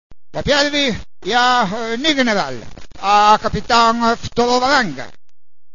Festa di fine corsi
In occasione della classica festa di conclusione dell' anno sociale, ben due allestimenti "plen air" nel cortile di Palazzo Rinuccini: